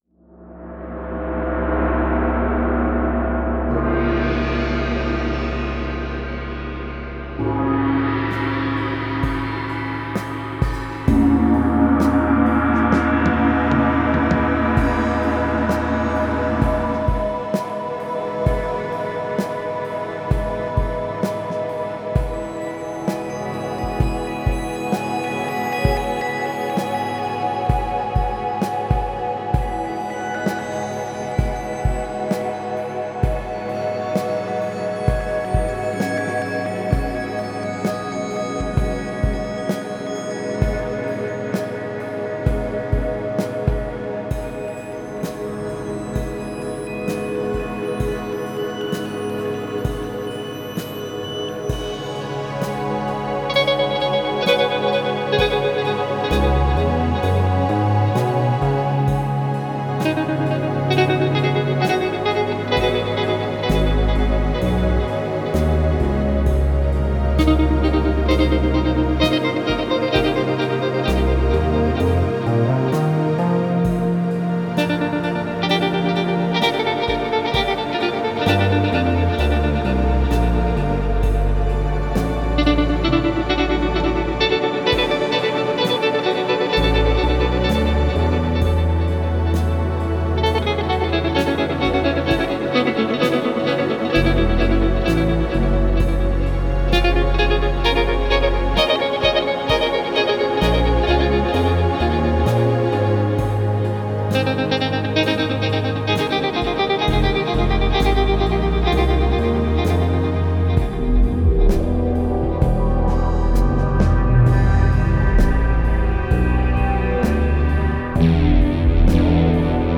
mit psychedelischer Wucht eine weitere barocke Harmoniefolge